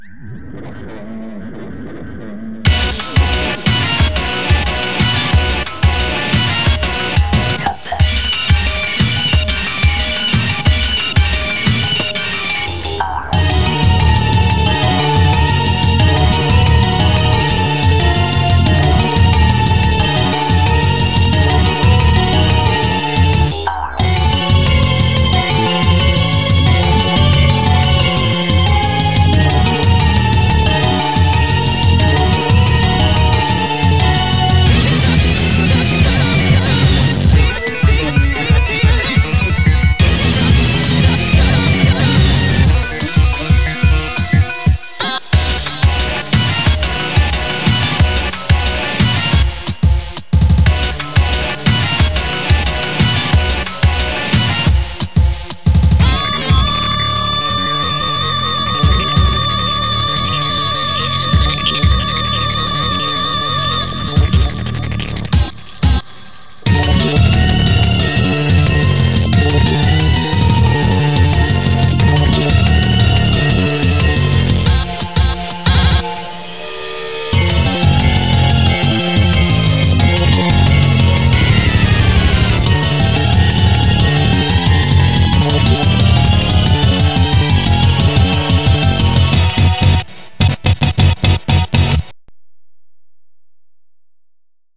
BPM180
Audio QualityPerfect (High Quality)
Better quality audio.